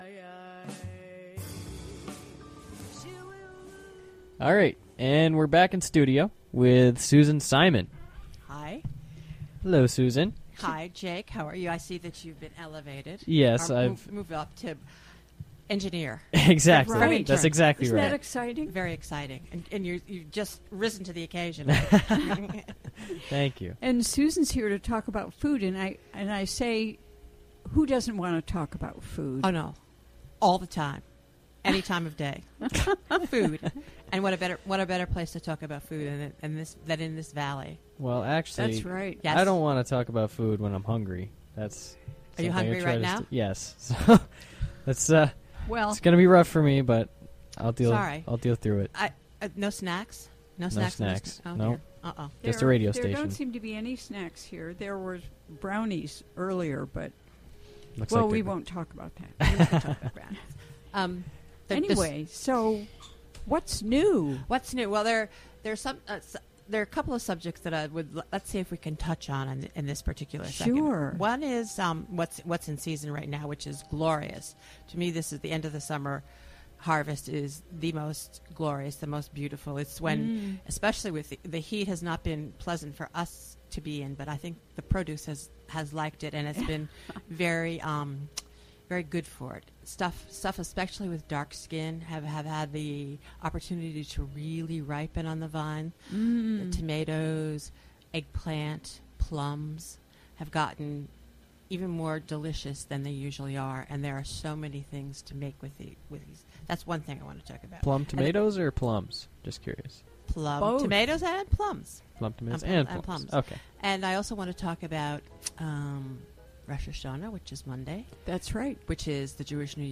Interview recorded during the WGXC Afternoon Show.